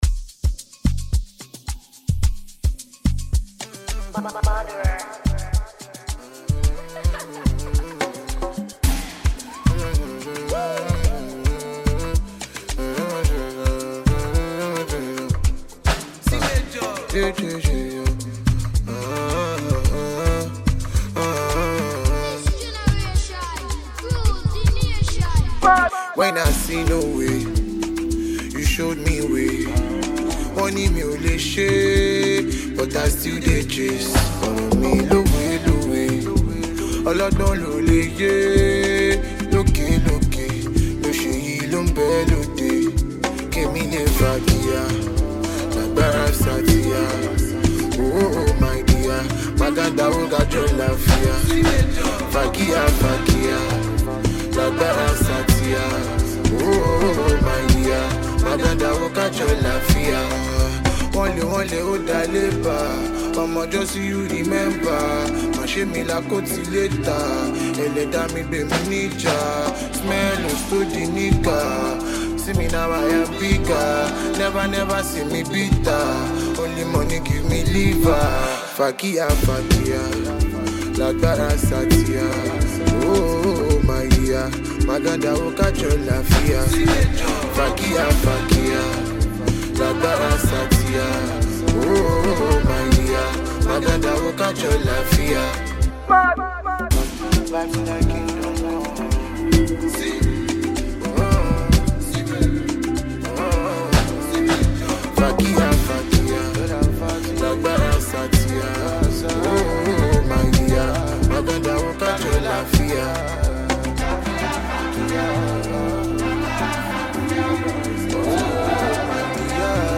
Nigerian talented singer
mid-tempo joint